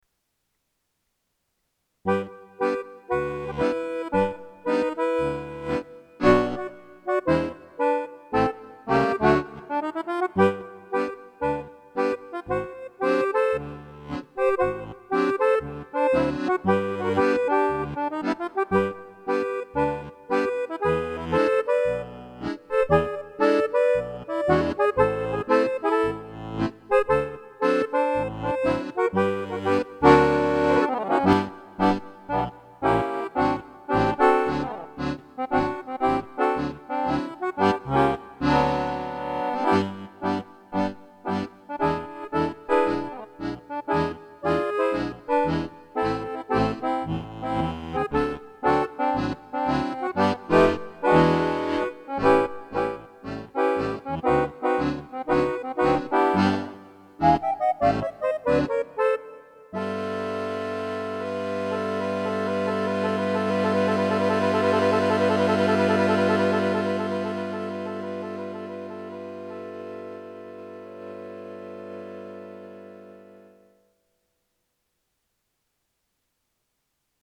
Mein nächstes Rätsel könnte von der Konstruktion her ein Kirchenlied sein - auch der Titel klingt danach. Beim ersten Durchlauf klingt es nach Kirchenlied, wie man es sich so vorstellt - beim 2. Durchlauf dasselbe Lied nach der Gesangsbuchrevision von 1980...